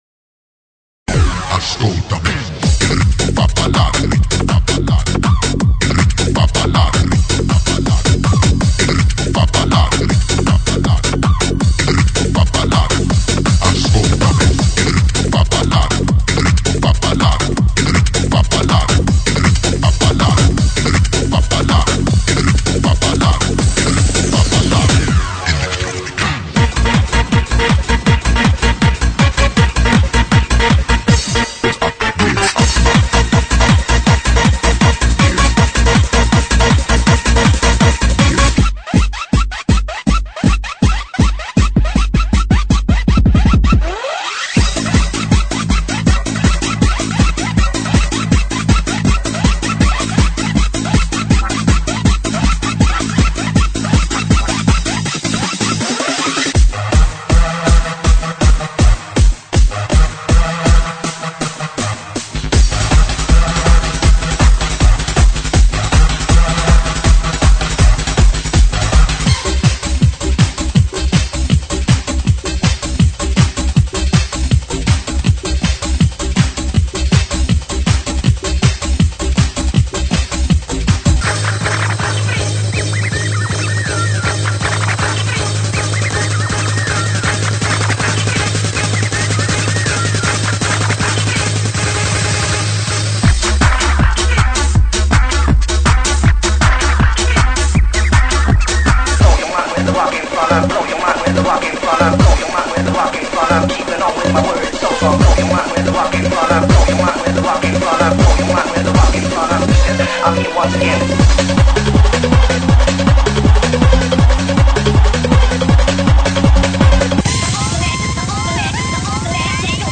GENERO: LATIN RADIO
DANCE ELECTRONICA.